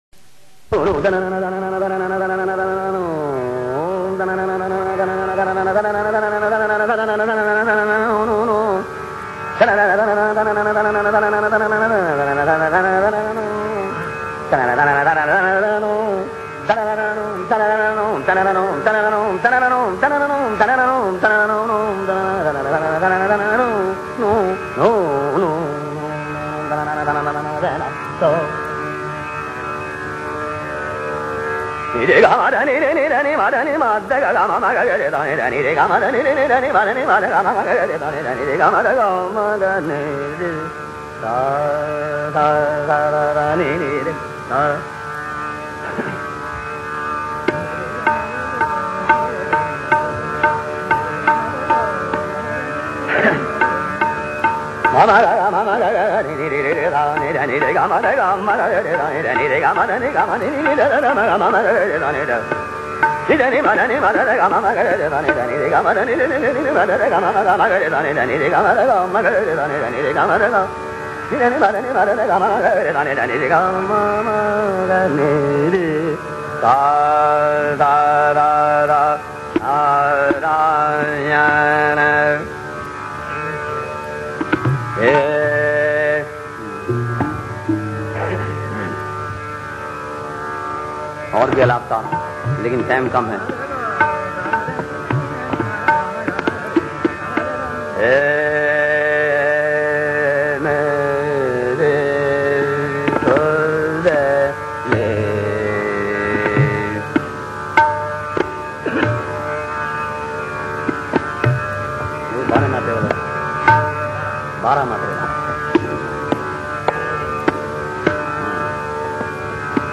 Puriya (Alaap) Puriya (Vilambit & Drut)
Especially starting at 10:37 until 17:50…incredible notes and superb voice.
2011 at 8:34 pm A tue Agra gharana rendition.
A genuine khayal maestro.